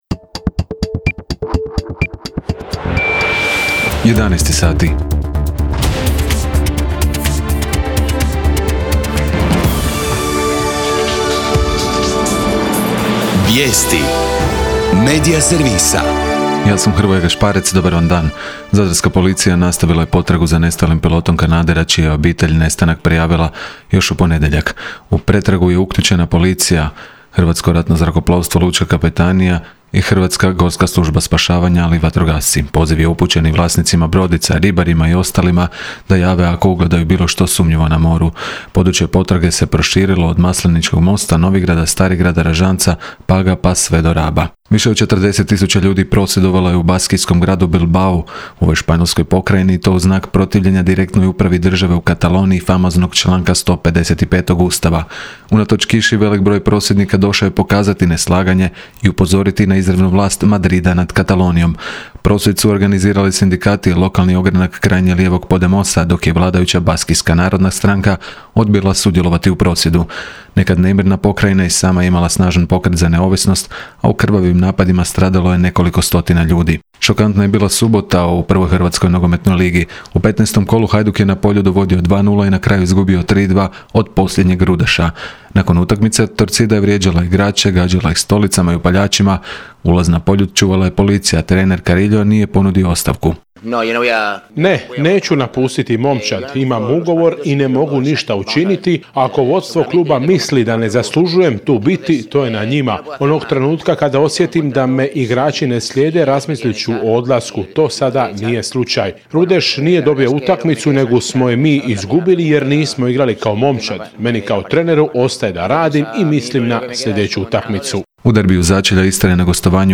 VIJESTI U 11